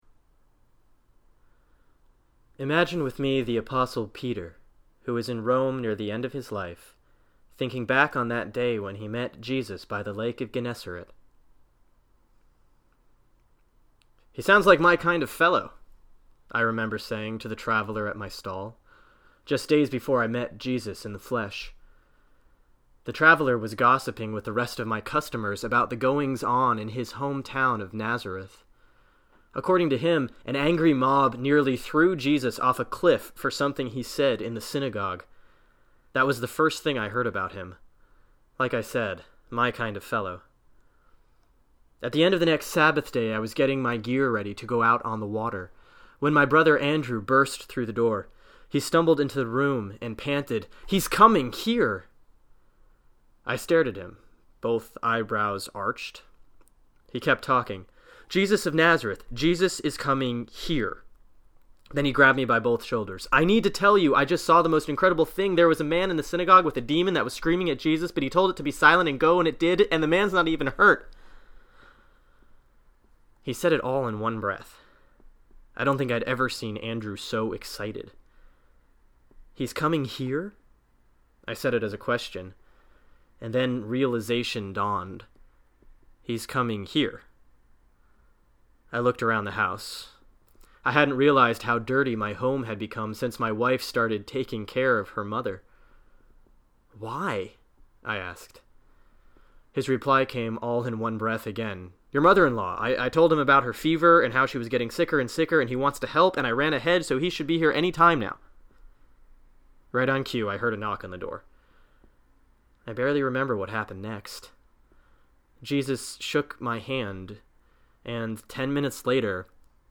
(Sermon for February 7, 2010 || Epiphany 5, Year C, RCL || Luke 5:1-11)
Accordingly, here’s a recording of the sermon as it would have sounded.